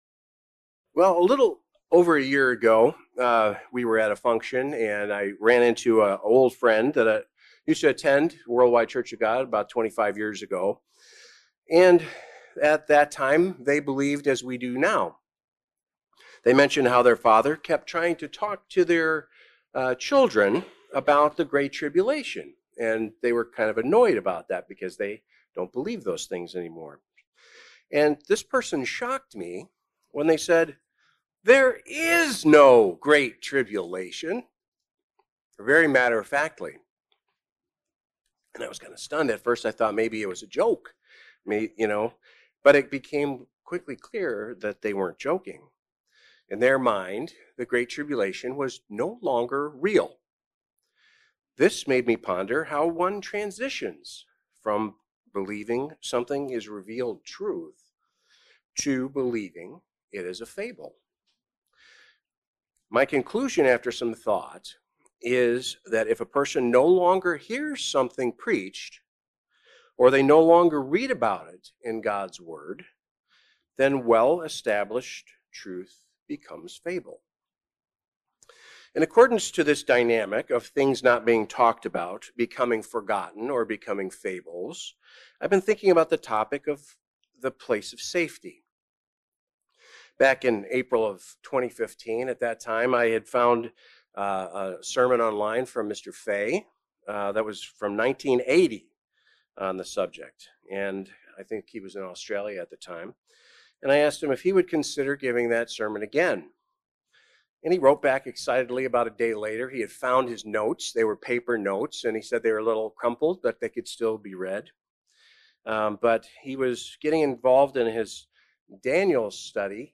Sermons
Given in Beloit, WI